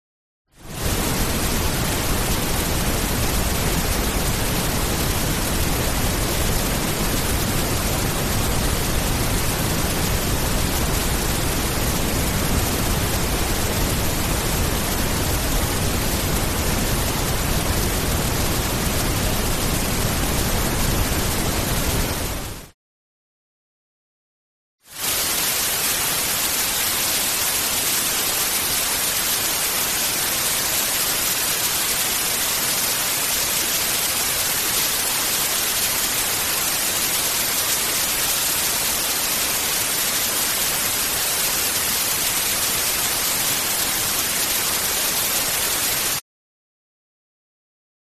На этой странице собраны разнообразные звуки белого шума, включая классическое шипение, помехи от телевизора и монотонные фоновые частоты.
Розовый шум для сна